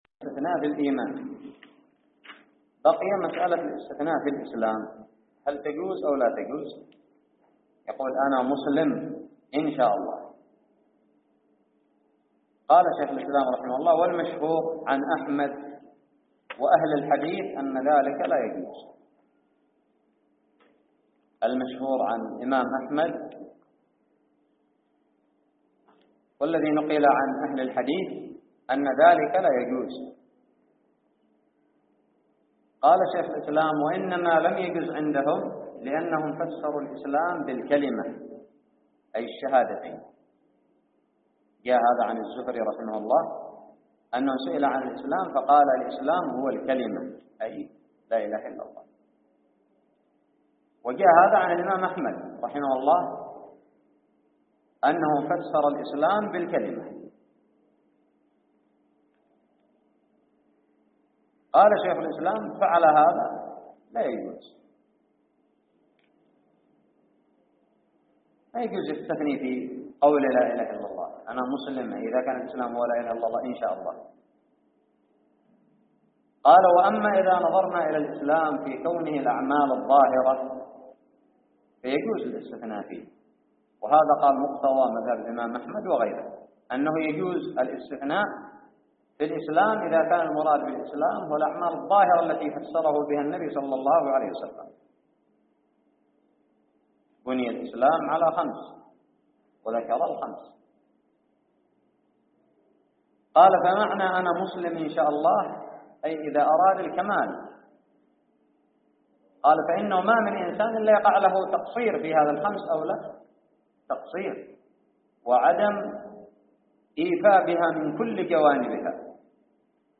ألقيت في دار الحديث بدماج